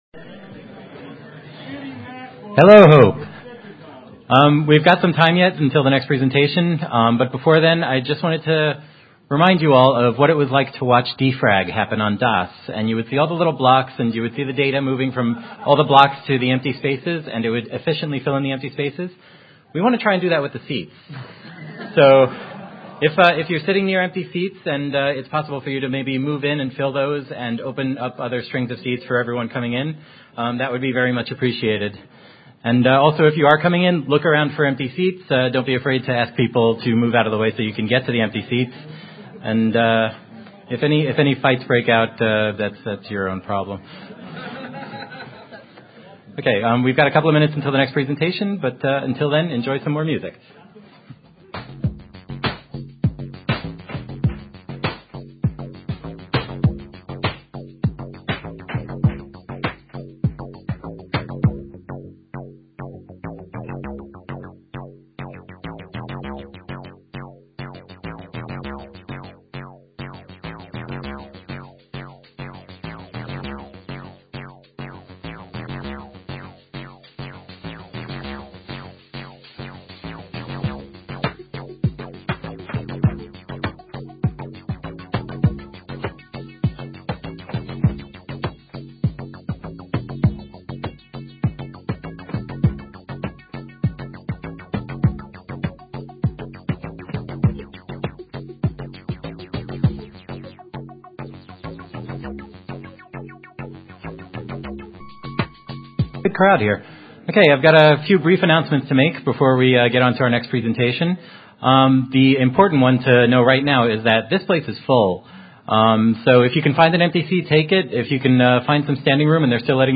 [HOPE X] Schedule
This talk will discuss the various technologies that law enforcement, intelligence agencies, and private industry use to track individual movements.